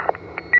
mic_click_off.ogg